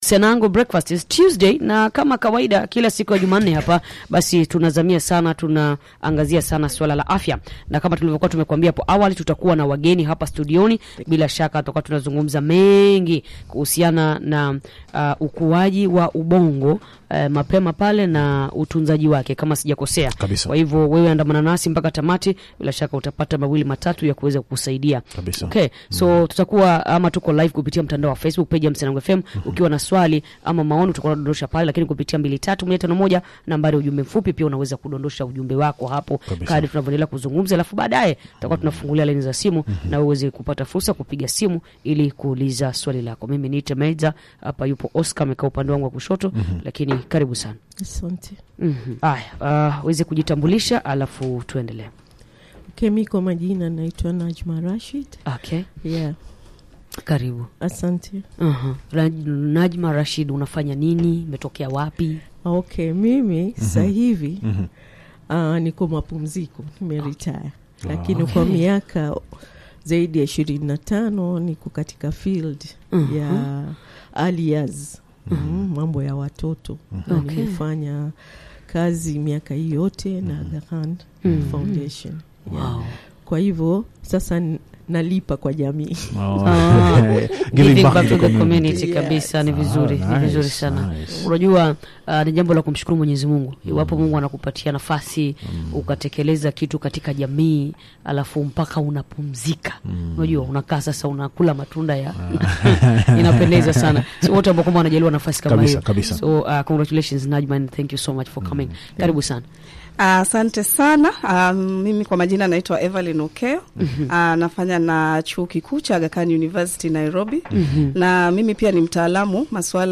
In 2018, the WHO, UNICEF, and the World Bank Group launched the Nurturing Care Framework, which serves as a strategic guide for countries and programs on how to invest in Early Childhood Development (ECD) for maximum impact. In this episode, our early childhood development specialists explain what ECD is, how experiences shape the brain and the different types of early experiences that can influence brain development, how families can provide responsive caregiving, the community’s role in ECD, among other important aspects of ECD.